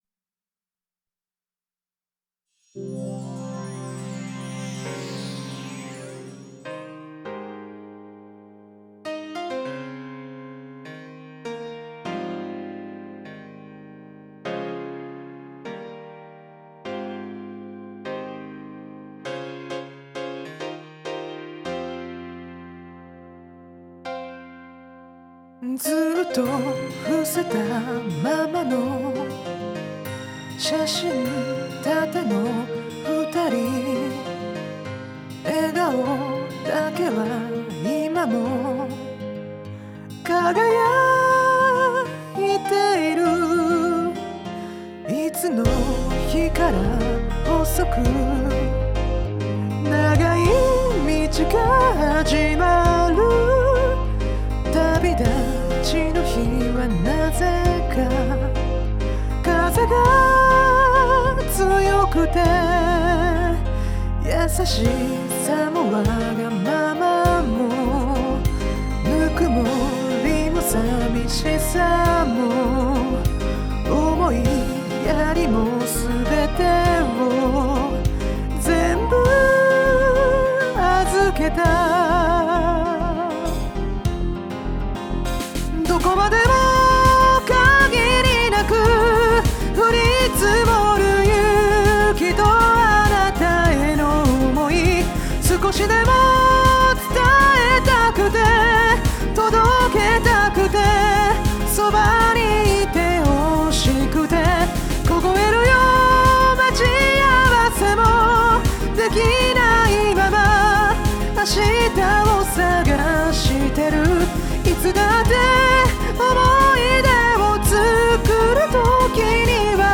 講師の歌唱音源付き記事
音量注意！
※カラオケ音源はこちらからお借りしました。